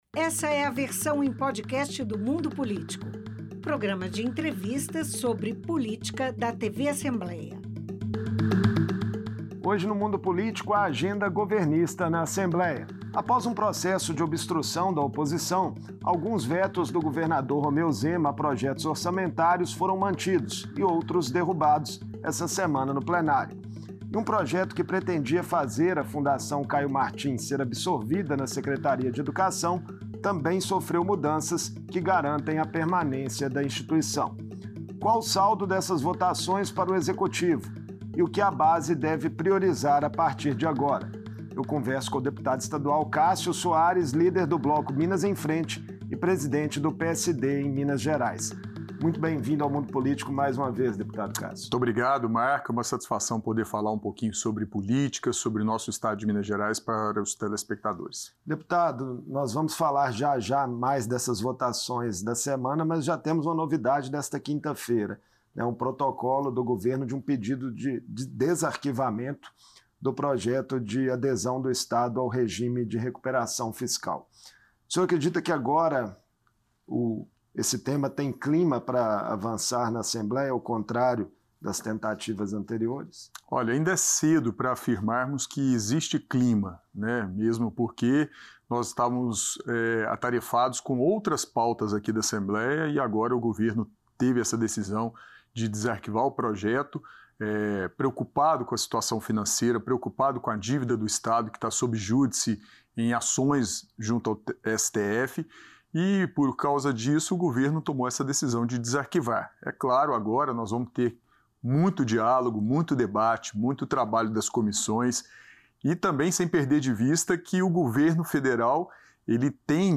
O governador Romeu Zema enviou a Assembleia um pedido de desarquivamento do projeto de adesão ao acordo do Regime de Recuperação Fiscal. Em entrevista